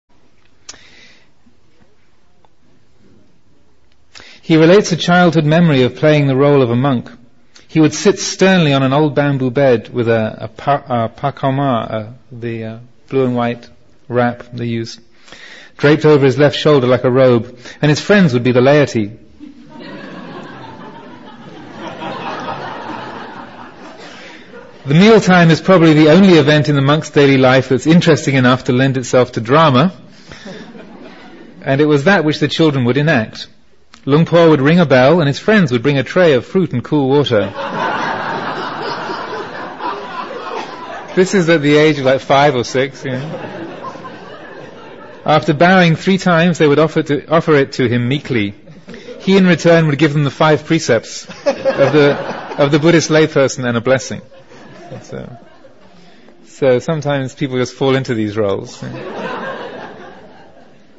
3. Reading from the draft biography: Young Chah plays at being a monk.